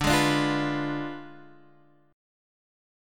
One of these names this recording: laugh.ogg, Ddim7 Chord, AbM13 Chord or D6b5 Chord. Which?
Ddim7 Chord